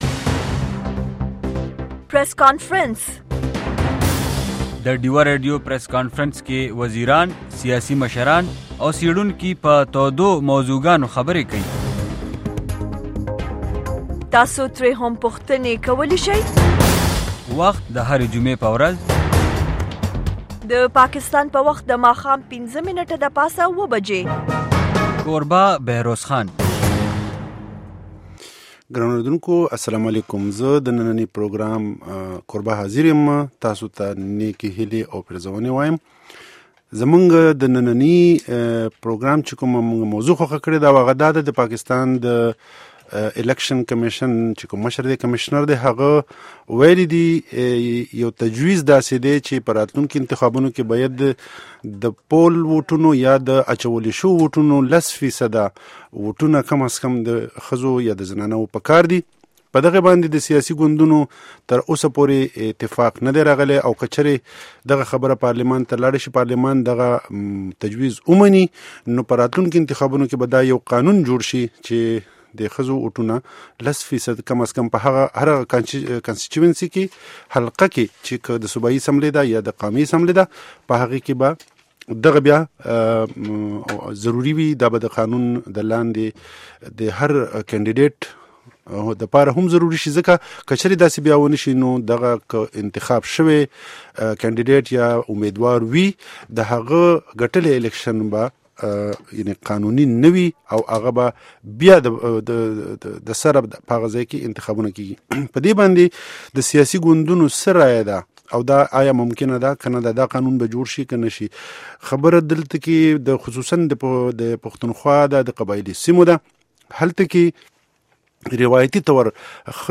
Deewa Radio Press Conference